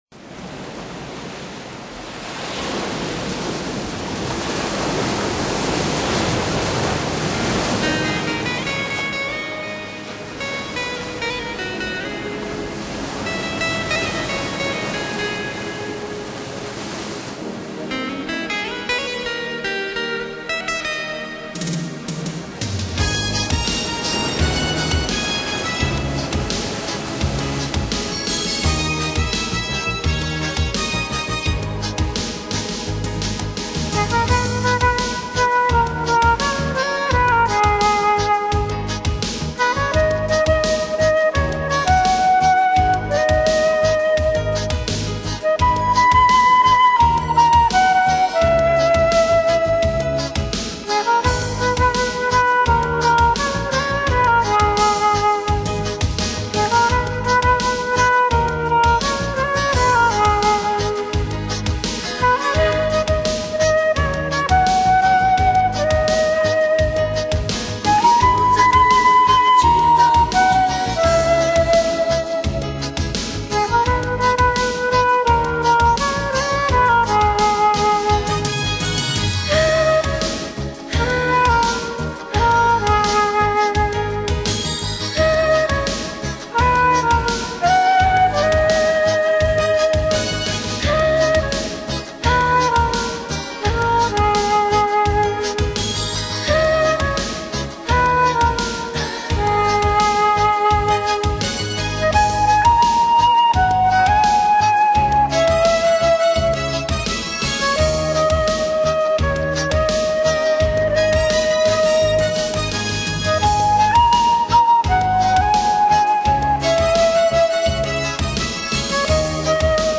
纯净清新的大自然音乐，让您的心情获得轻松宁静。
长笛